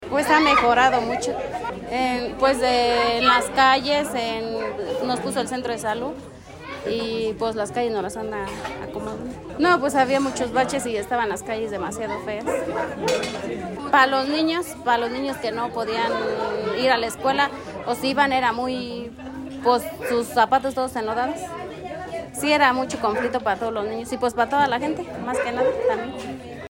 AudioBoletines
habitante de la comunidad Venado de Yóstiro